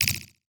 Message Bulletin Echo 4.wav